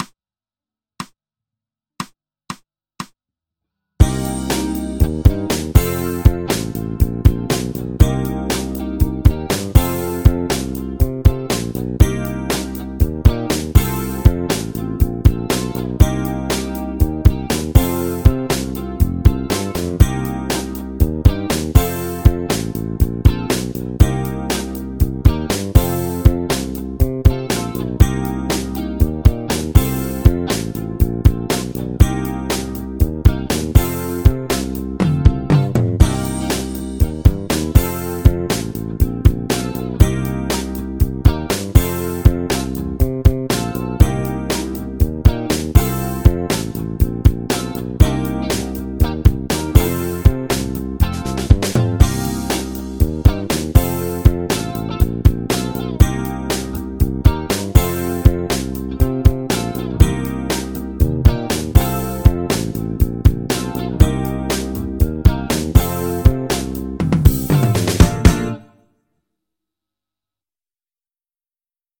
ドリアン・スケール ギタースケールハンドブック -島村楽器